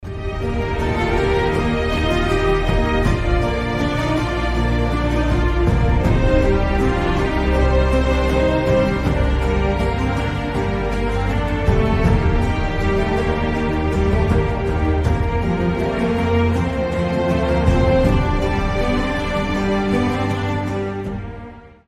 без слов
оркестр